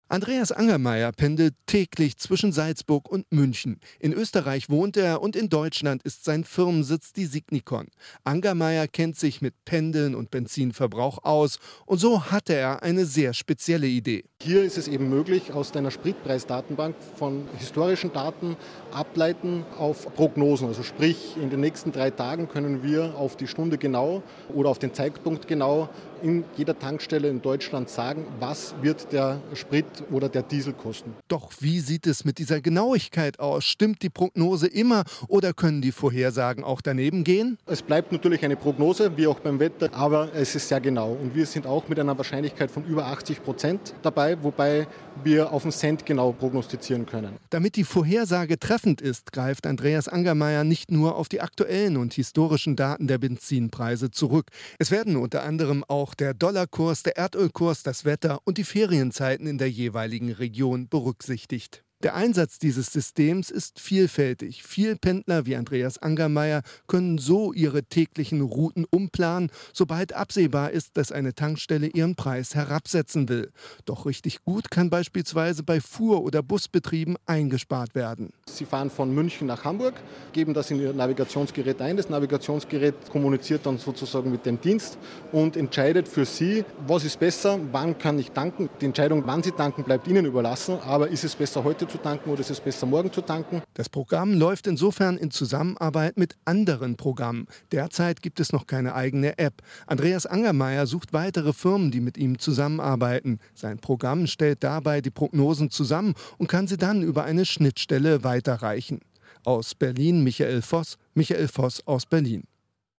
Posted in Auto, Berichte von der IFA, Computer, Digital, IFA, Medien, Nachrichten, Radiobeiträge, Software, Wirtschaft